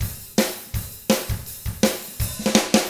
164ROCK F5-R.wav